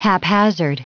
Prononciation du mot haphazard en anglais (fichier audio)